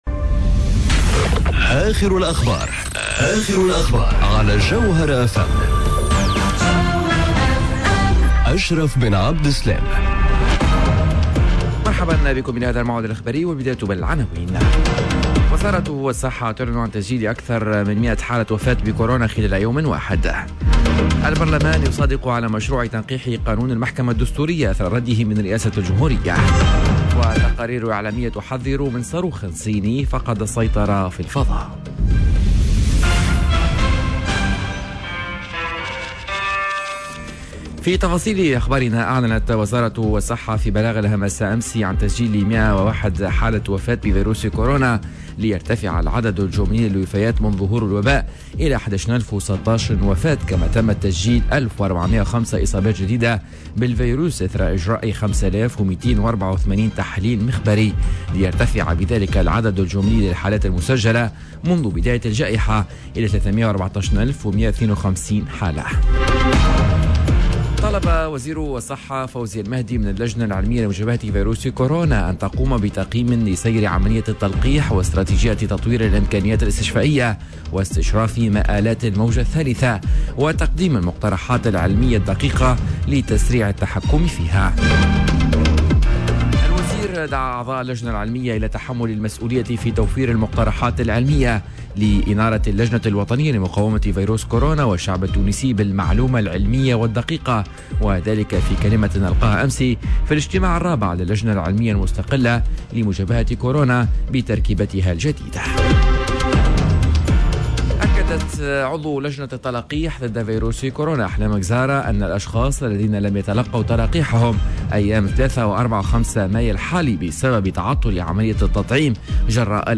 نشرة أخبار السابعة صباحا ليوم الإربعاء 05 ماي 2021